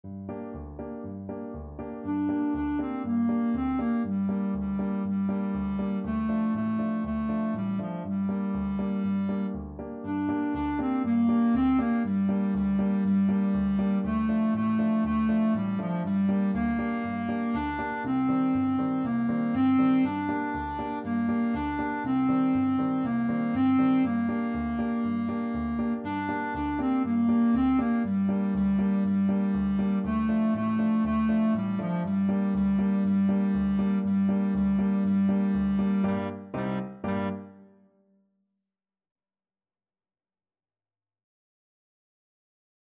4/4 (View more 4/4 Music)
Allegro vivo (View more music marked Allegro)
World (View more World Clarinet Music)